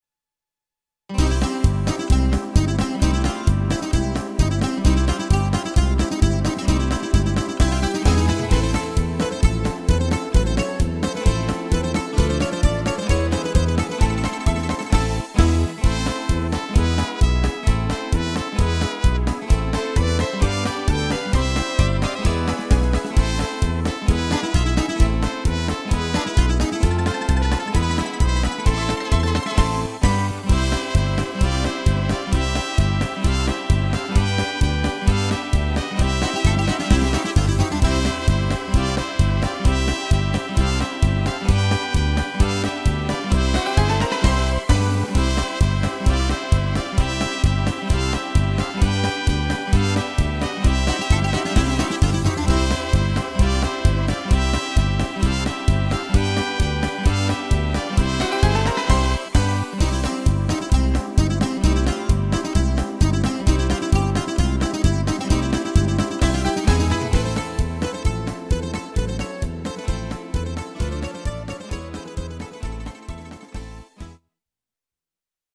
Karsilamades